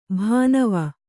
♪ bhānava